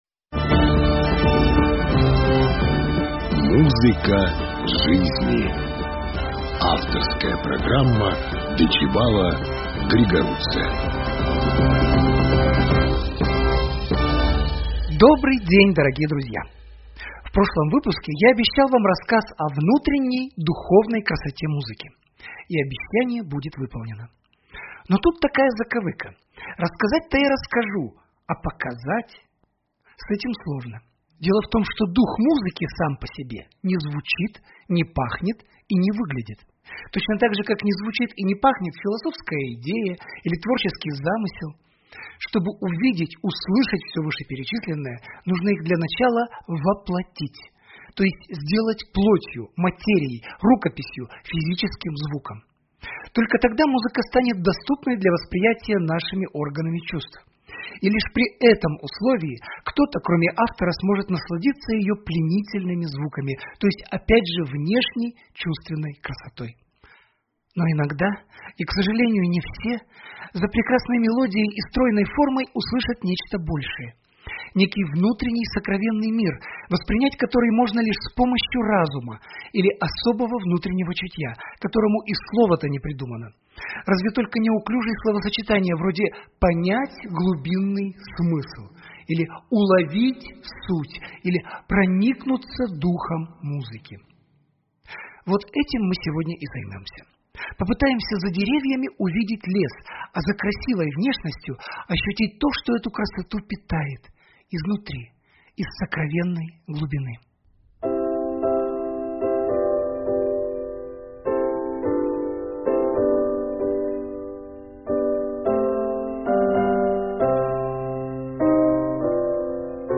Познавательно-просветительская авторская передача музыковеда